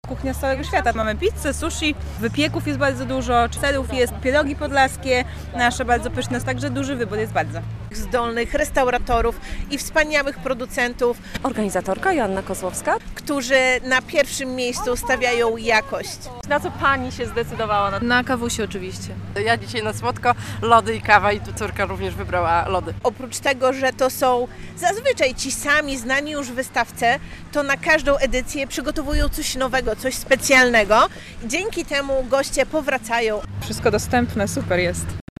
Podlaskie Śniadanie Mistrzów - relacja